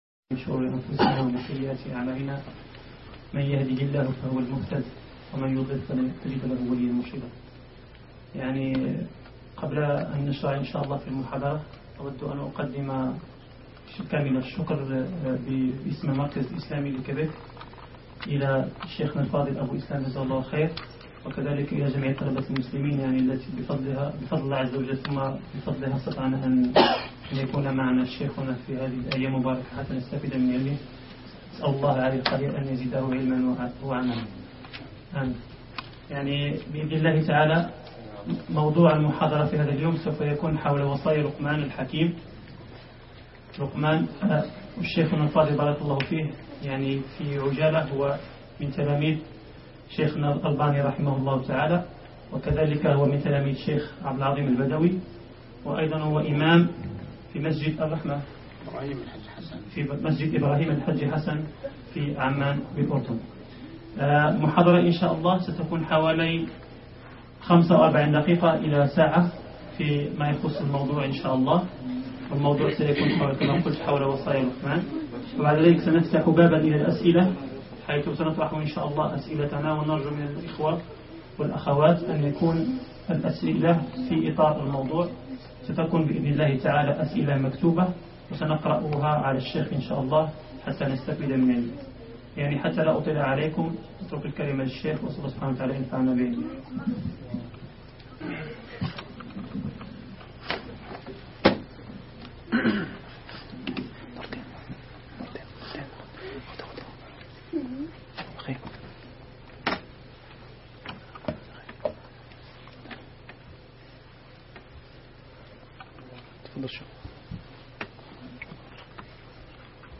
وصايا لقمان الحكيم لإبنه _ محاضرات بكندا